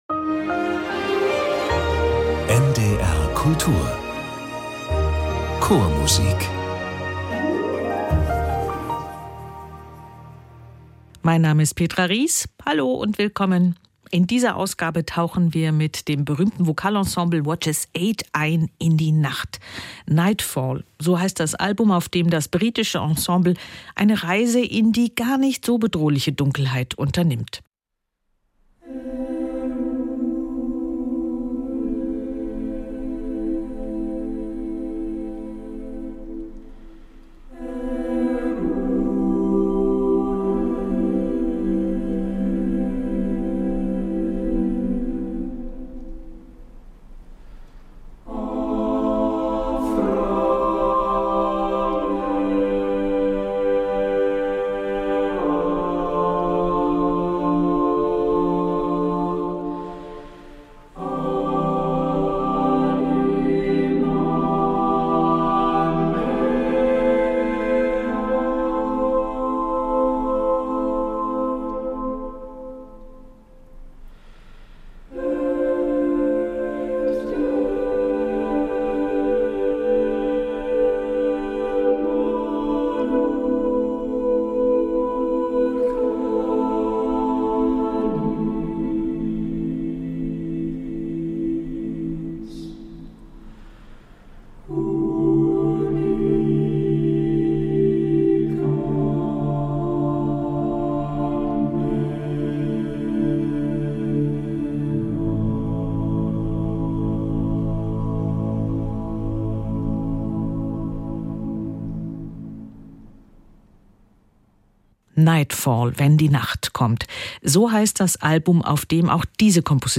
Chormusik aus allen Jahrhunderten, aus allen Ländern und Zeiten und Chöre aus aller Welt.